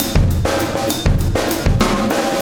Extra Terrestrial Beat 16.wav